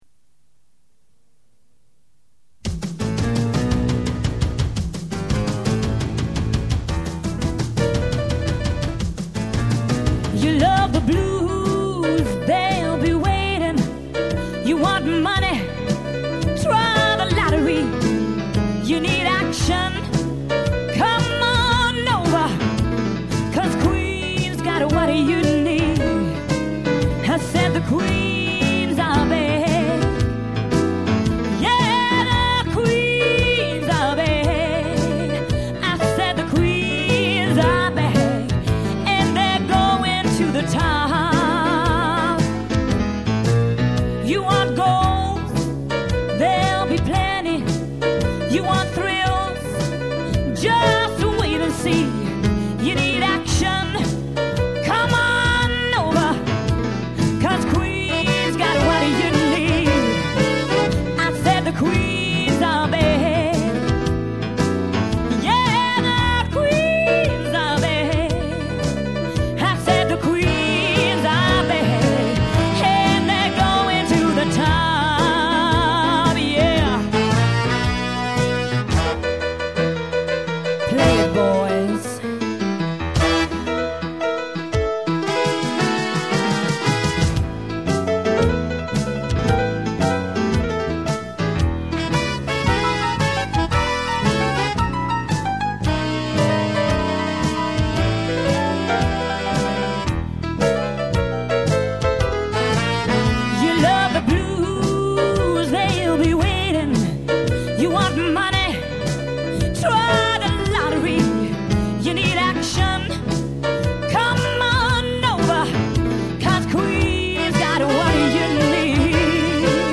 traditional music with lyrics